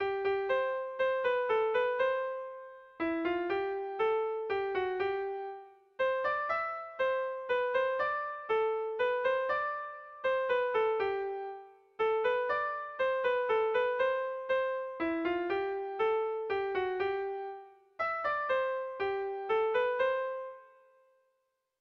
Melodías de bertsos - Ver ficha   Más información sobre esta sección
Kontakizunezkoa
ABD